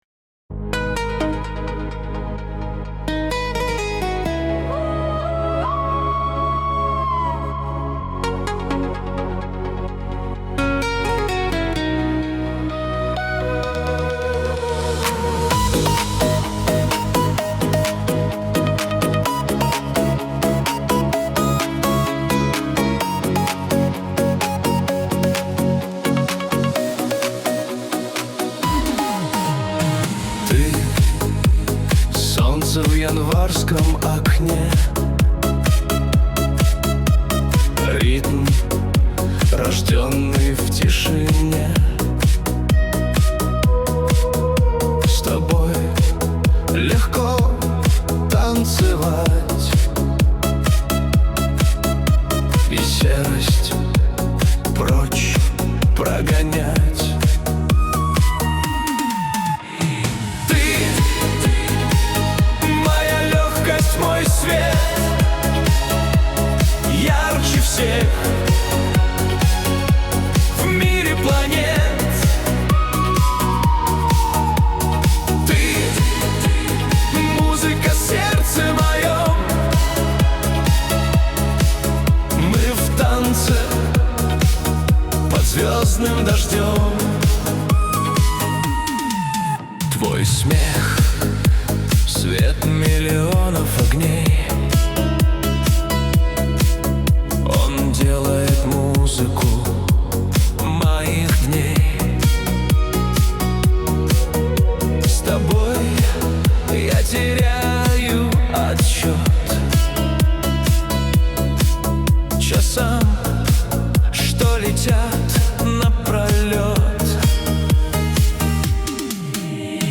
Стихи, Нейросеть Песни 2025